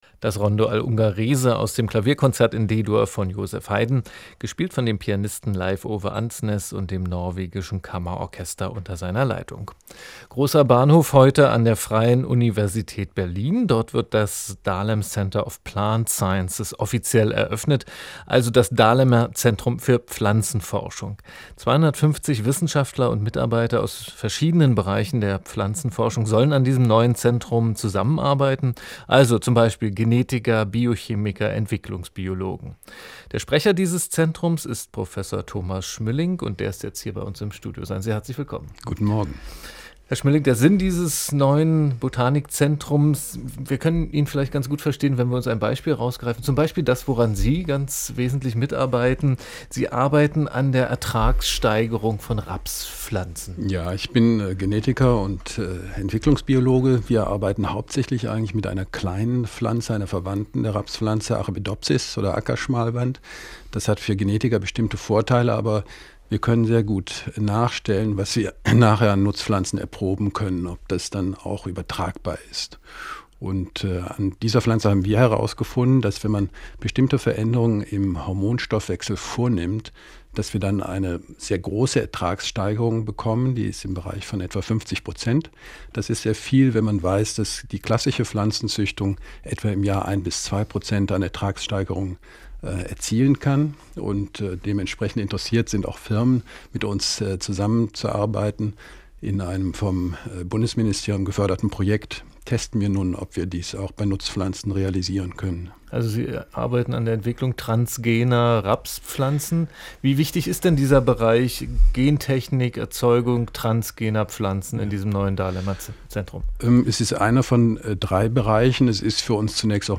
• Interview radio-eins: Eröffnung des Dahlem Centre of Plant Sciences (30.09.2010) (